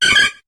Cri de Nirondelle dans Pokémon HOME.